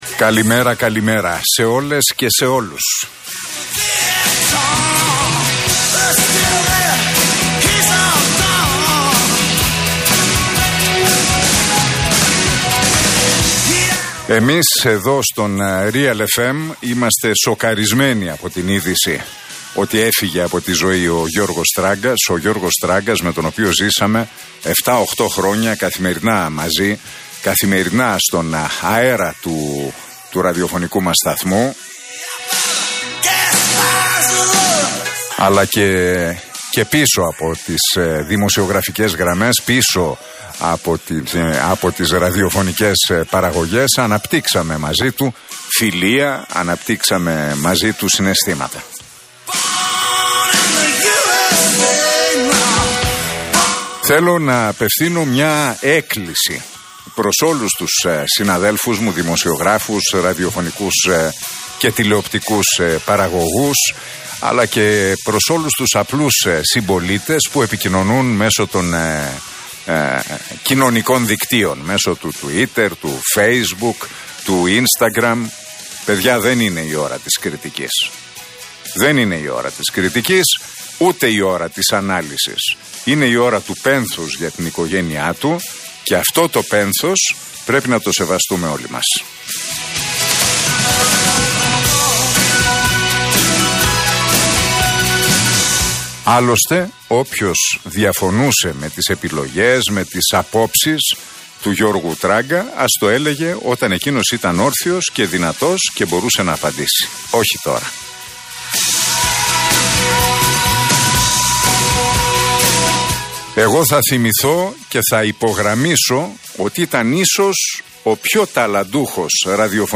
Ακούστε το σημερινό σχόλιο του Νίκου Χατζηνικολάου στον Realfm 97,8.